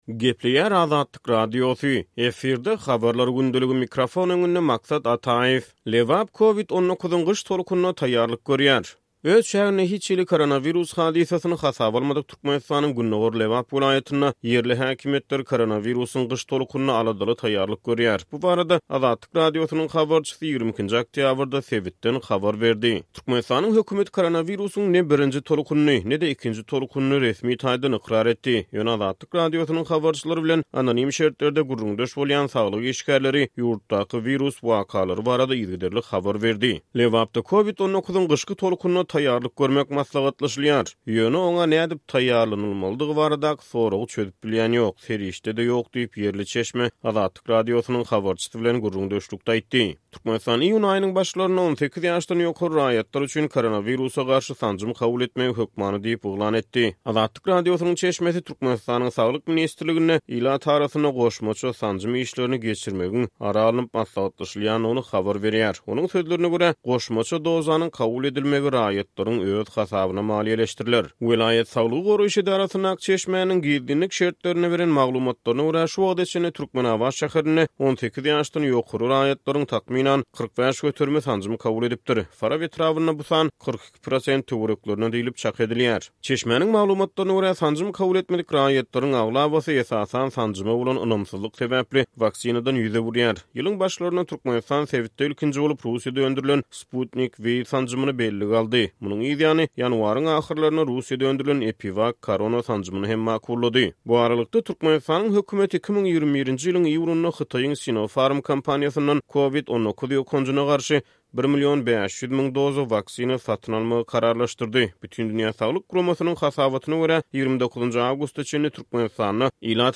Öz çäginde hiç hili koronawirus hadysasyny hasaba almadyk Türkmenistanyň gündogar Lebap welaýatynda ýerli häkimiýetler koronawirusyň gyş tolkunyna aladaly taýýarlanýar. Bu barada Azatlyk Radiosynyň habarçysy 22-nji oktýabrda sebitden habar berdi.